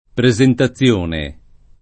presentazione [ pre @ enta ZZL1 ne ]